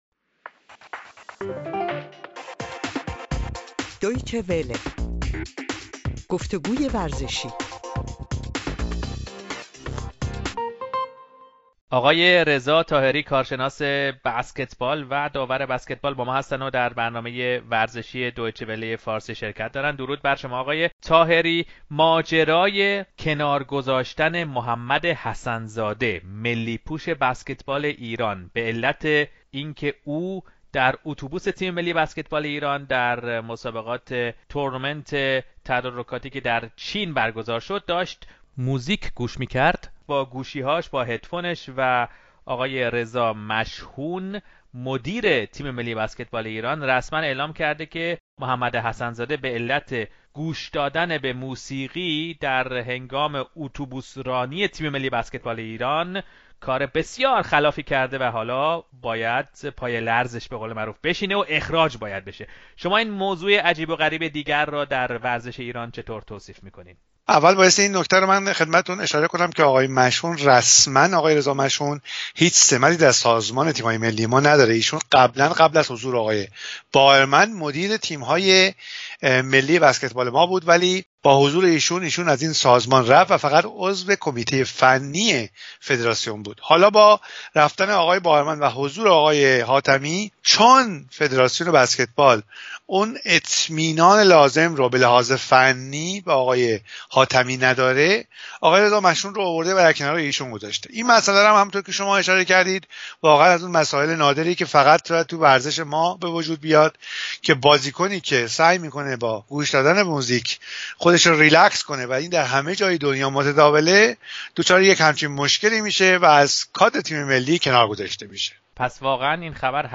این در حالی است که تیم ملی ایران از هفته آینده در مسابقات آسیا در لبنان به میدان می‌رود. گفت‌وگو